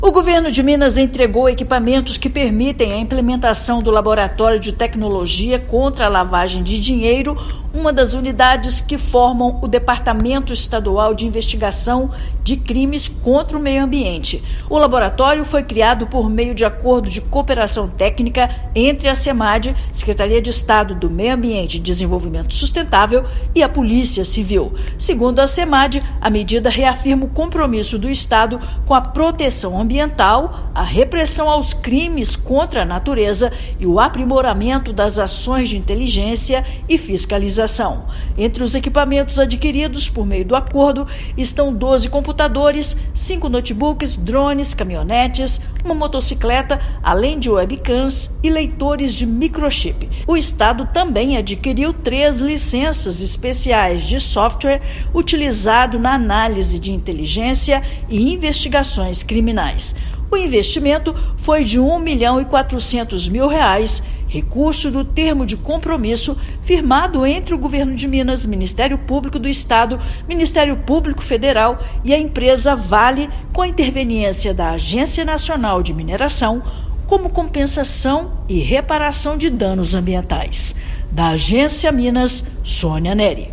Cooperação técnica entre Semad e Polícia Civil intensifica integração em atividades de fiscalização e inteligência ambiental. Ouça matéria de rádio.